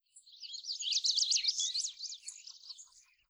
Birds 2.wav